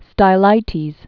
(stī-lītēz), Saint AD 390?-459.